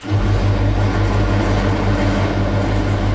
grind.ogg